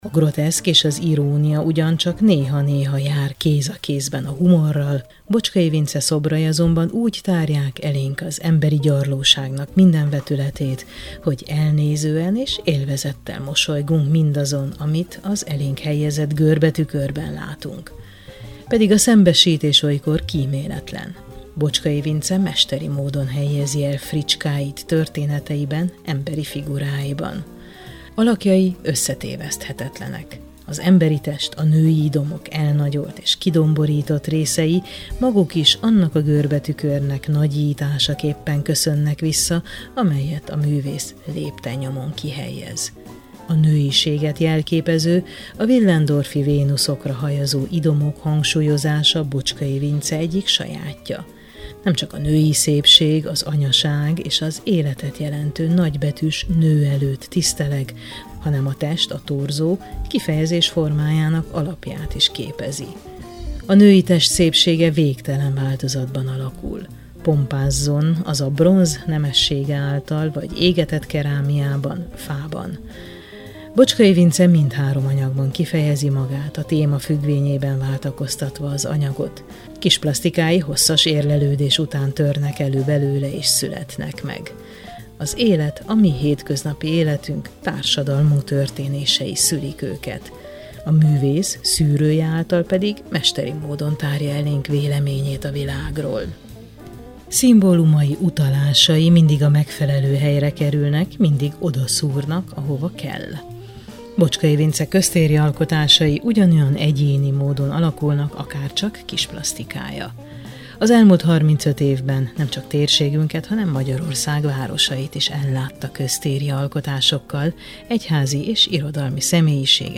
Életművem egyetlen sorozat – Beszélgetés